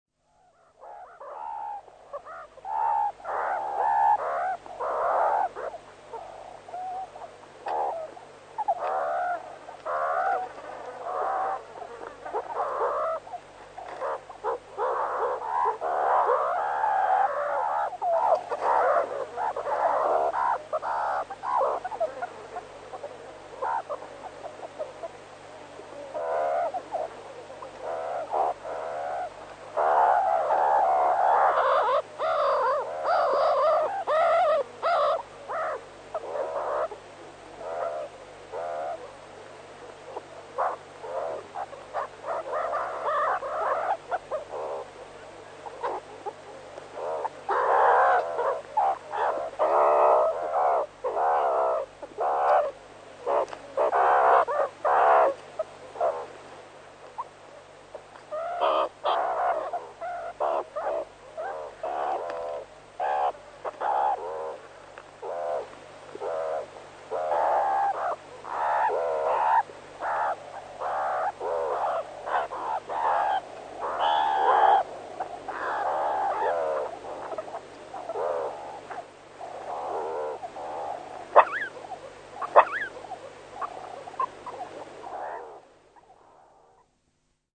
Звуки общения сурикатов в дневное время под солнцем (запись из южной Африки)